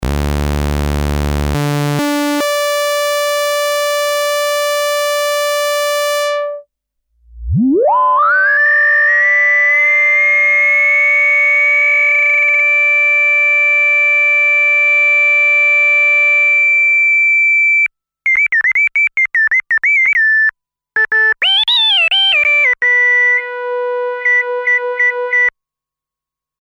Das ist ein SH09. Sägezahn, dann Filter zu, Reso hoch und dann die Cutoff hochgezogen bis der Resonanzton des Filters mit der Tonhöhe des VCOs übereinstimmt. Dann habe ich den VCO runtergezogen und nur den Reso stehen lassen und dann wieder mal reingemischt.